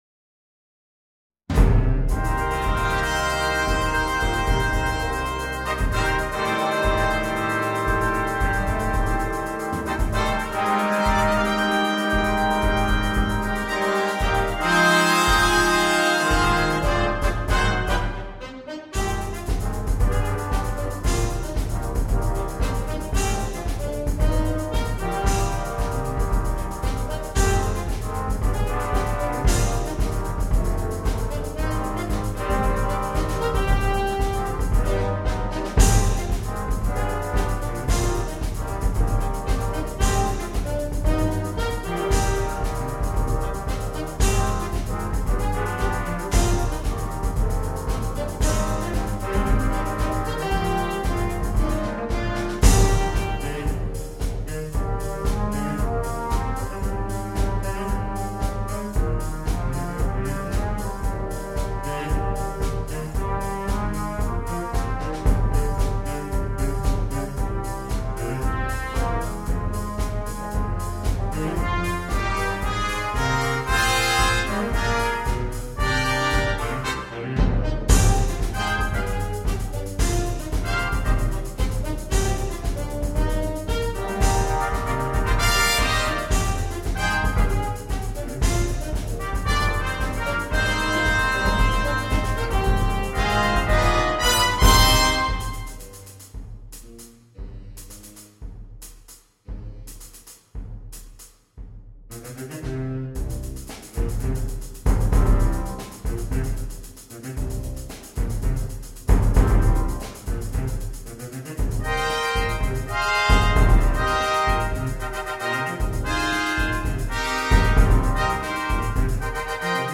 Ноты Slow dance для биг-бэнда.